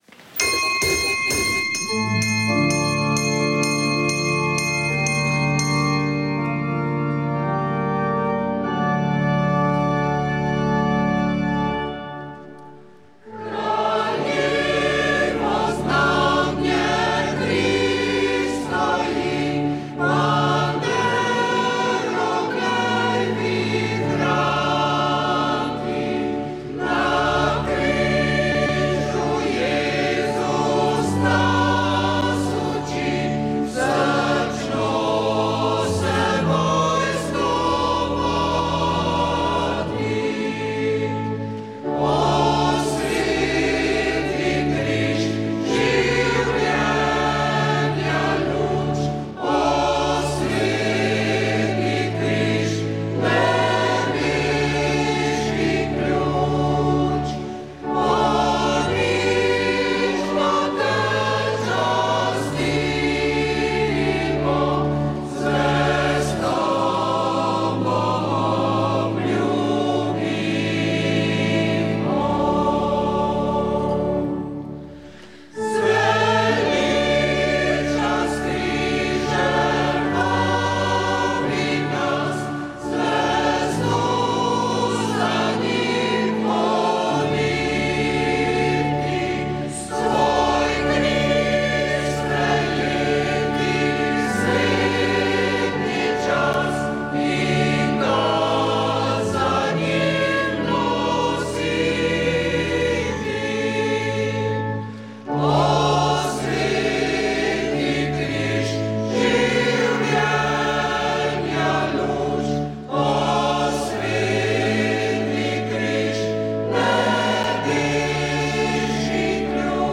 Sveta maša
Sv. maša iz cerkve Marijinega vnebovzetja v Novi Štifti pri Ribnici